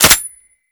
lowammo_dry_automatic.wav